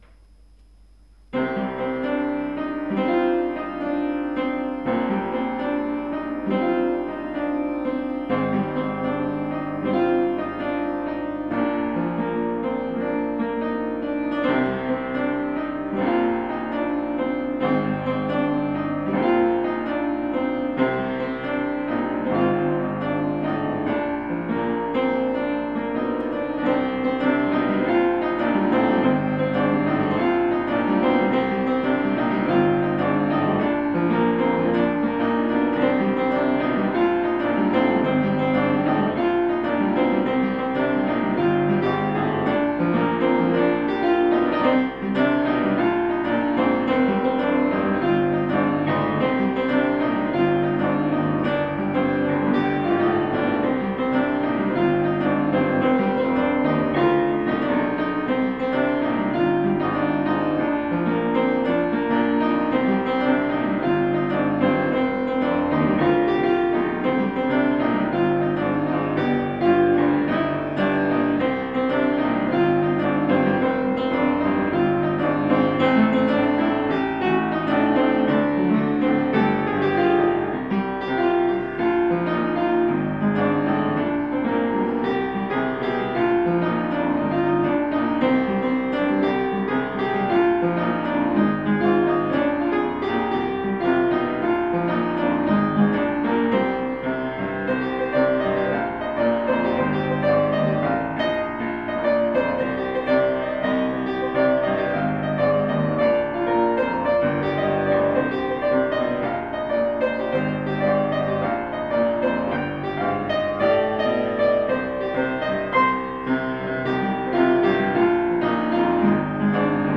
היצירה נקראת על שם המקצב שלה, שהוא שבע שמיניות.
קצת מונוטוני מידי.
יש את המנגינה המובילה שחוזרת על עצמה וזה מאוד נכון ויפה.